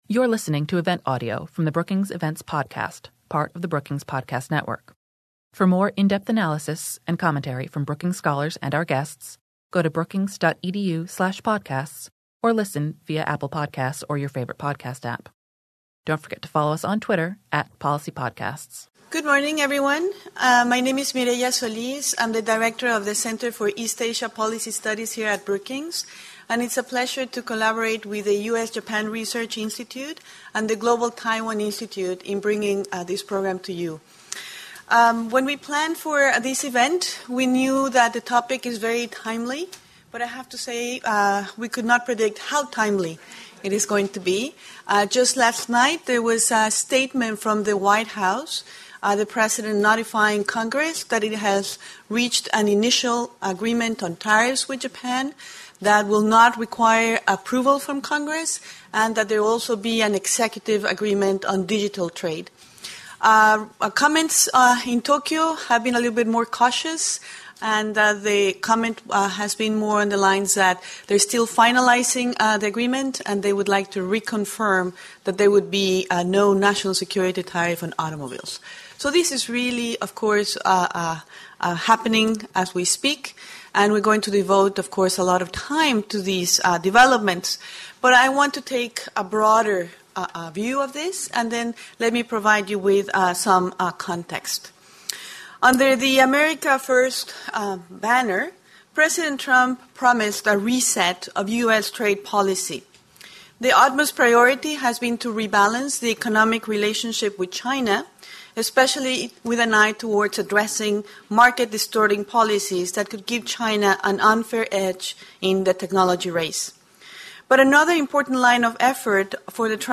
On September 17, the Center for East Asia Policy Studies at Brookings, the U.S.-Japan Research Institute, and the Global Taiwan Institute co-hosted a panel of experts to discuss U.S. trade bilateralism in Asia and issues surrounding Japan-U.S. and Taiwan-U.S. trade.